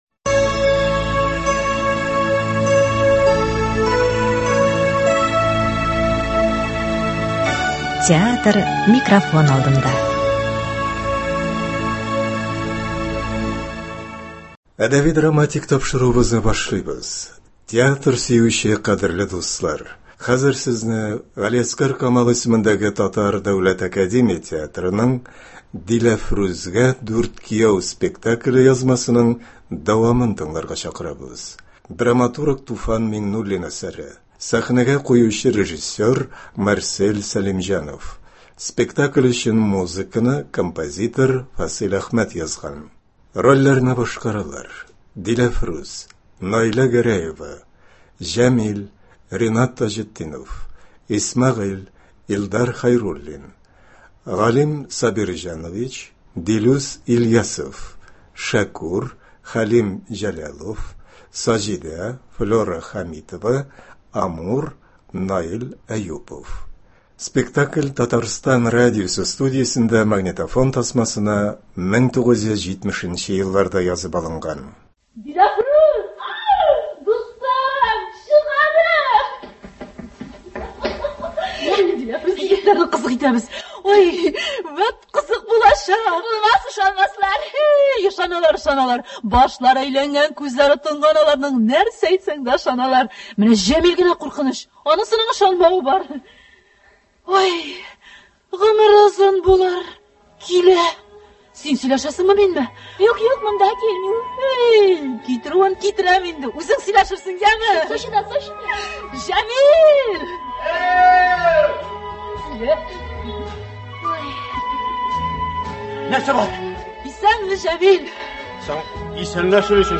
Театр сөюче кадерле дуслар, хәзер сезне Г.Камал исемендәге Татар Дәүләт академия театрының “Диләфрүзгә дүрт кияү” спектакленең радиовариантын тыңларга чакырабыз. Драматург Туфан Миңнуллин әсәре.
Спектакль Татарстан радиосы студиясендә магнитофон тасмасына 1970 елларда язып алынган.